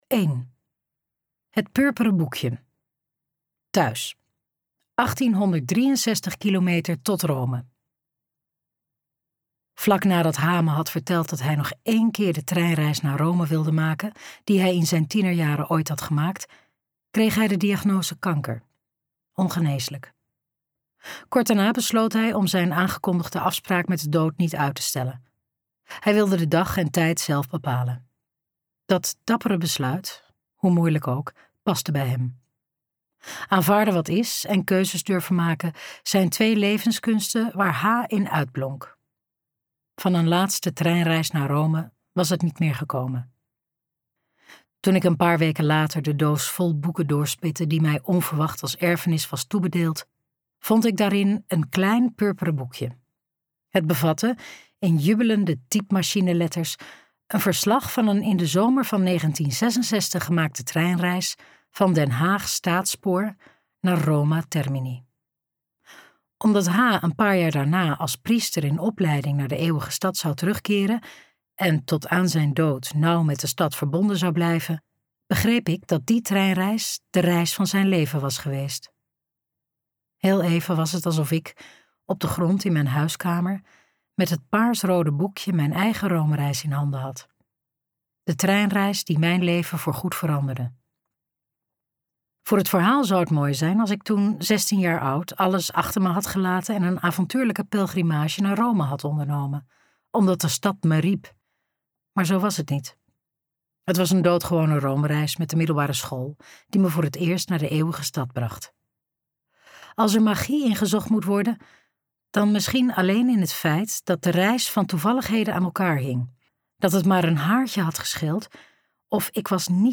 Romeinse sporen luisterboek | Ambo|Anthos Uitgevers